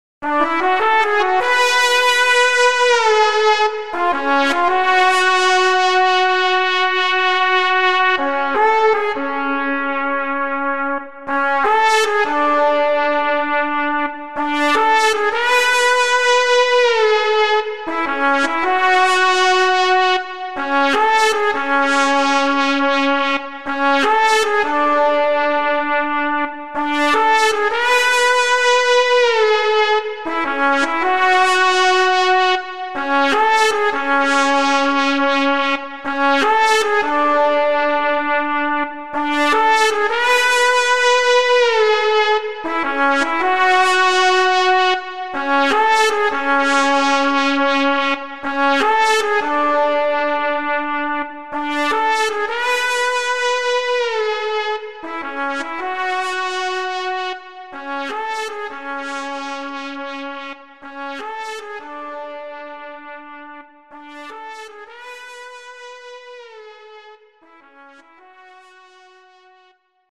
HALion6 : trumpet
Mexico Trumpets